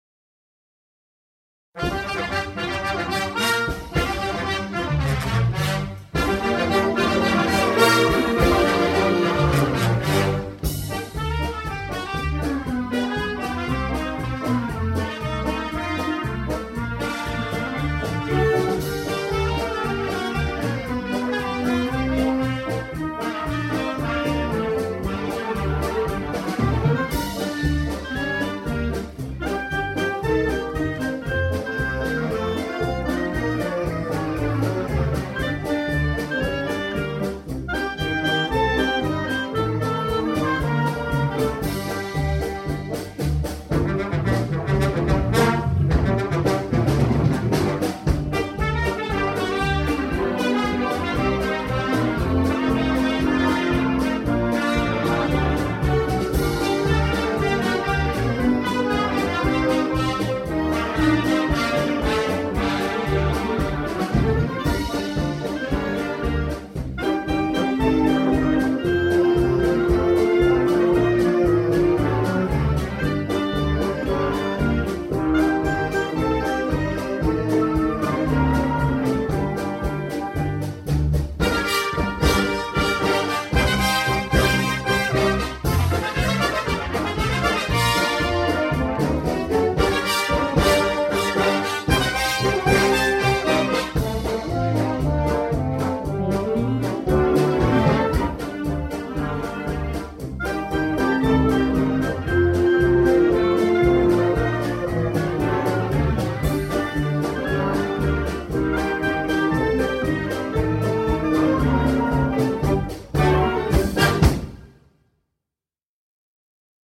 Scores for brass band
Romanian folk song